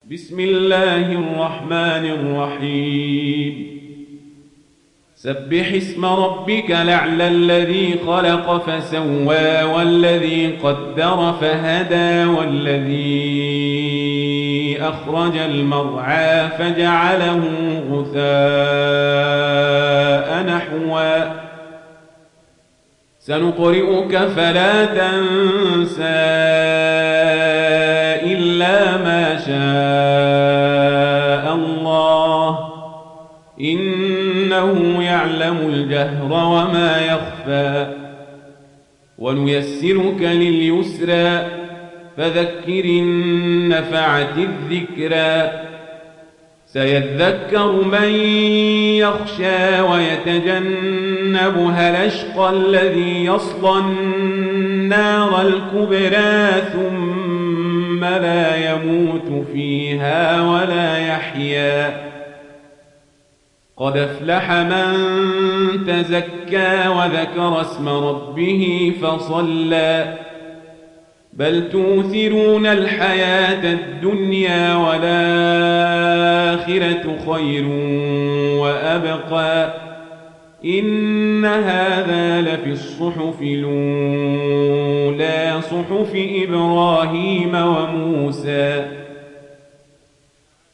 تحميل سورة الأعلى mp3 بصوت عمر القزابري برواية ورش عن نافع, تحميل استماع القرآن الكريم على الجوال mp3 كاملا بروابط مباشرة وسريعة